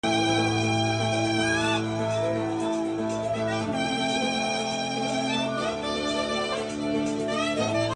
🎷 Saxophone in Lebanon, rockets sound effects free download
As the saxophone sings over a rooftop party in Lebanon, the sky lights up with Iranian rockets heading toward Israel. Music floats through the air — and so does war. A scene where celebration meets destruction.